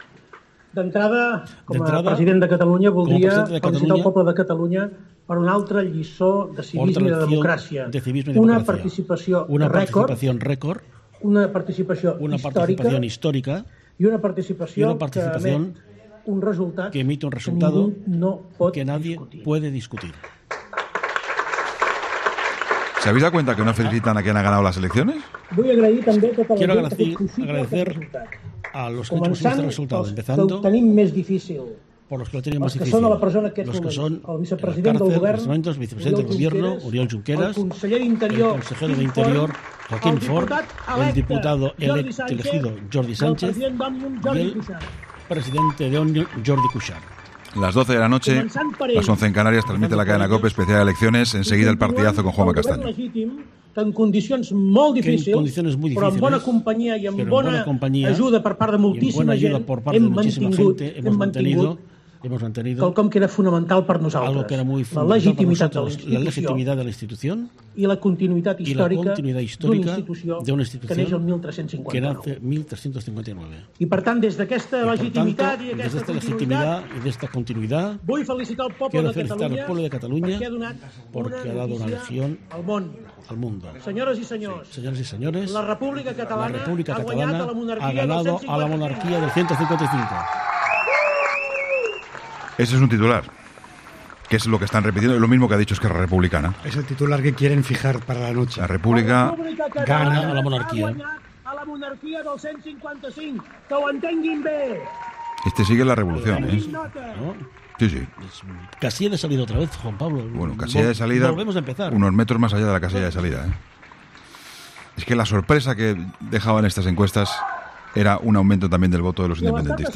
Carles Puigdemont ha comparecido eufórico desde Bruselas después de haber destrozado a las encuestas y haber convertido a Junts per Catalunya en la seguda fuerza más votada este 21-D con 34 escaños, que le han servido para sostener la mayoría absoluta para el bloque independentista.